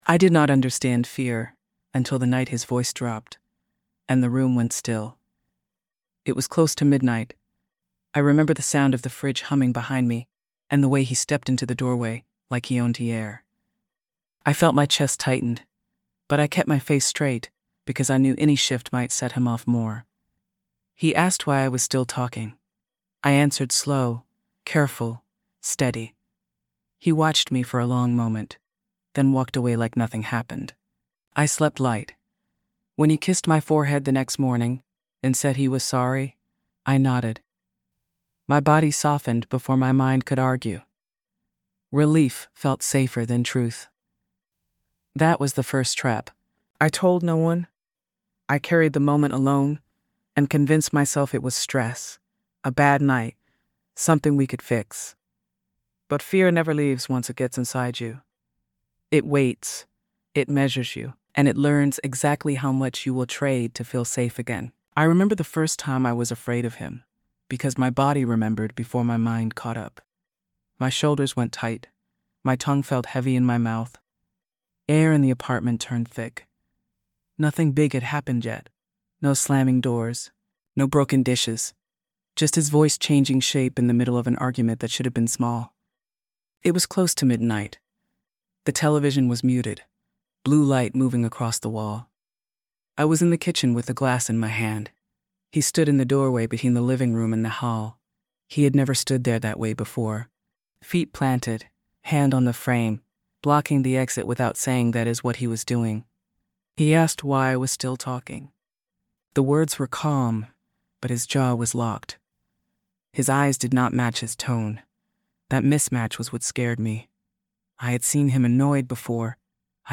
A woman tells her story in a raw first-person voice as she slips into, endures, and finally escapes a relationship built on fear. What begins as a single late-night moment of intimidation grows into financial control, isolation, emotional manipulation, and threats that tighten around her life piece by piece.